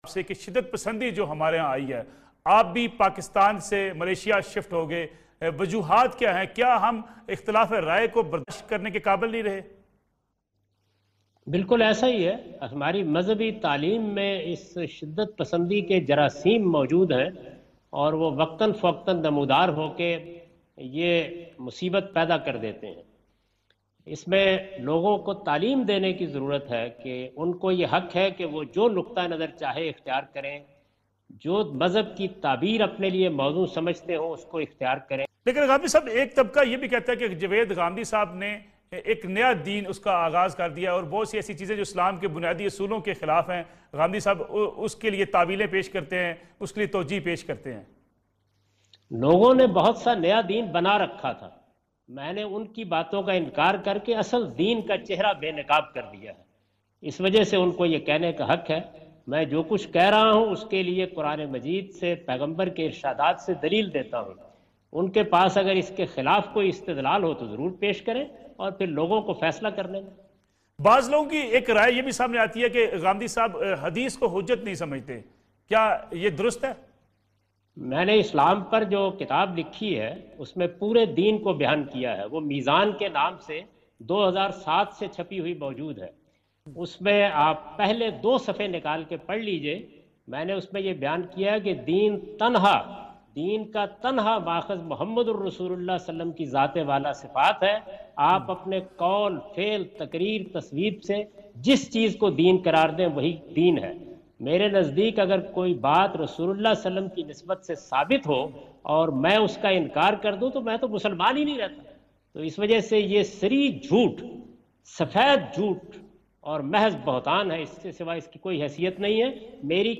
Category: TV Programs / Neo News /
In this program Javed Ahmad Ghamidi answer the question about "Have we lost Patience for difference of Opinion" on Neo News.